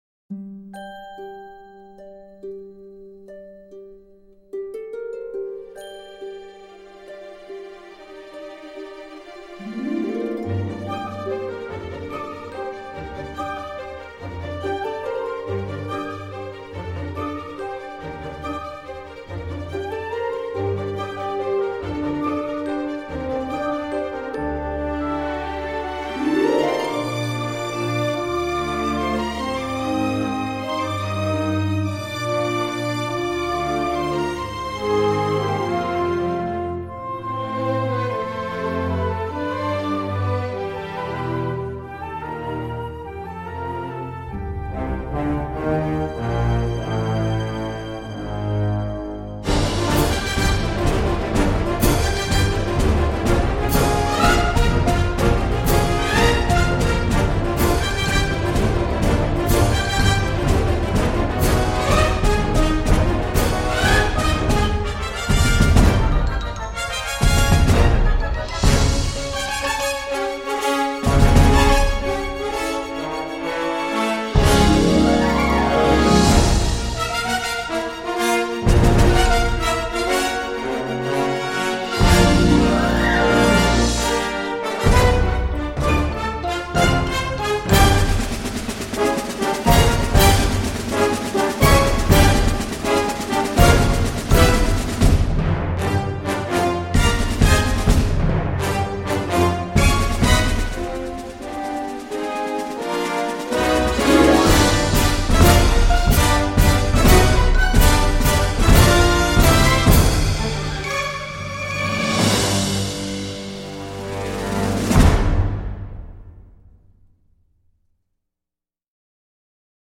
on saute de l’intimiste au martial avec un égal bonheur